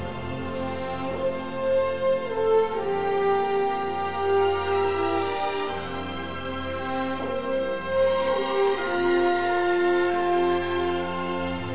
theme music (259K)